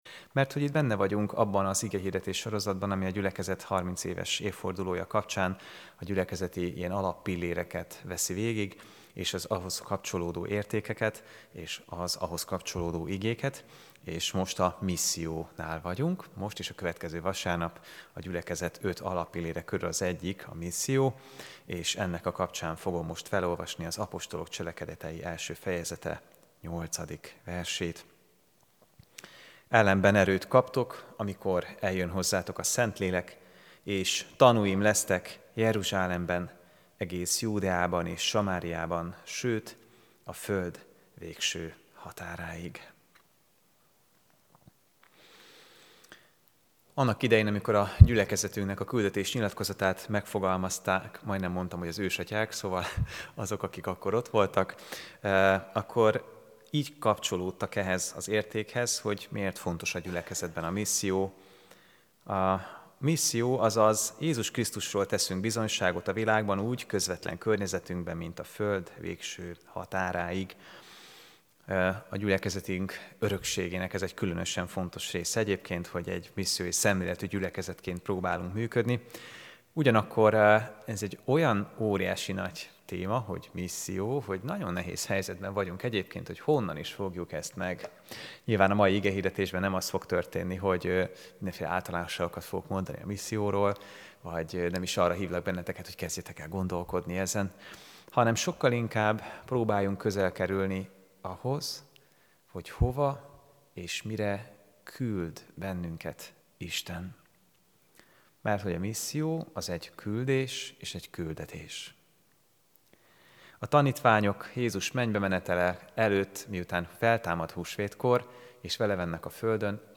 AZ IGEHIRDETÉS LETÖLTÉSE PDF FÁJLKÉNT AZ IGEHIRDETÉS MEGHALLGATÁSA